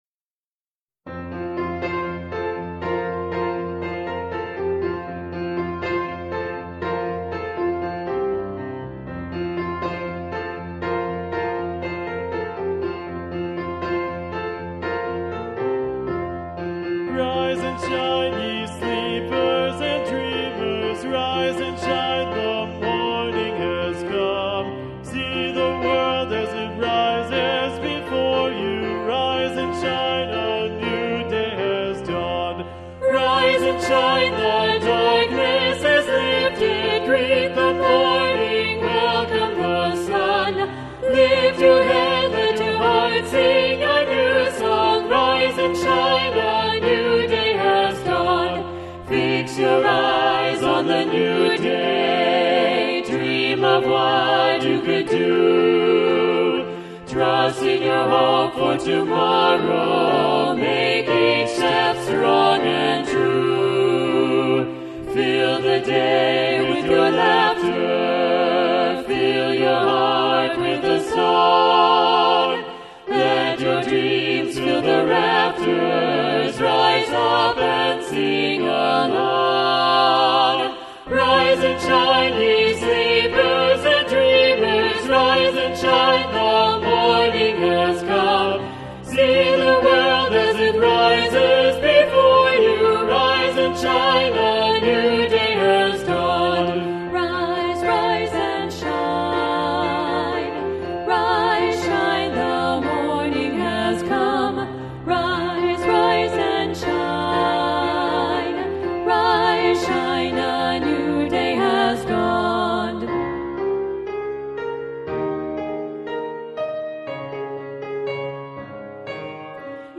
Voicing: SAT(B) and Piano